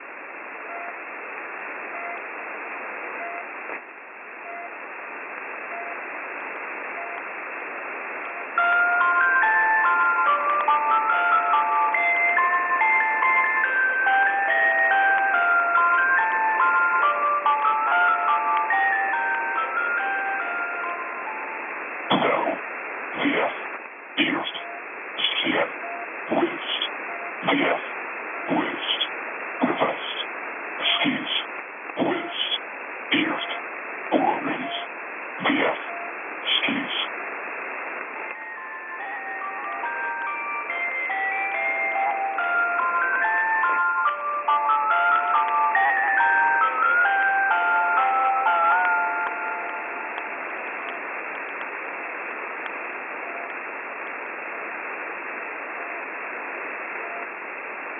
Oulun alueella on viime aikoina havaittu omituista radioliikennettä.